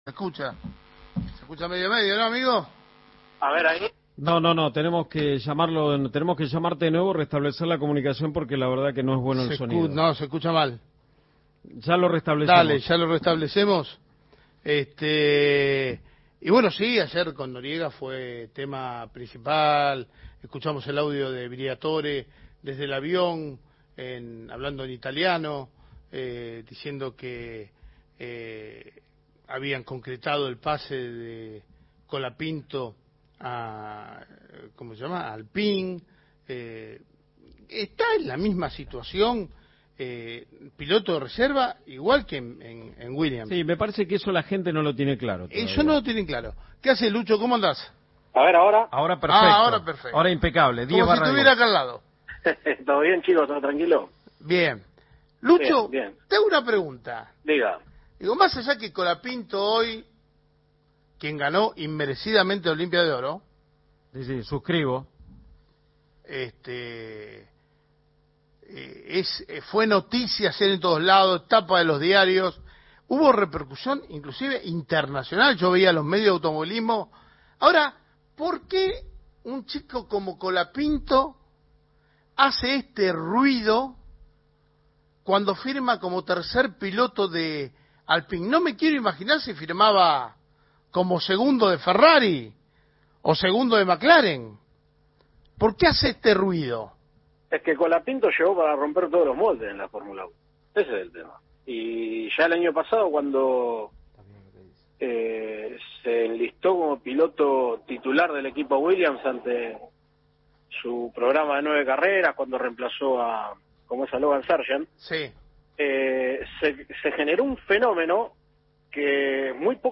El expiloto de automovilismo Osvaldo ‘Cocho’ López en diálogo con el equipo de Creer o Reventar opinó sobre la vuelta de Franco Colapinto a la […]
ENTREVISTA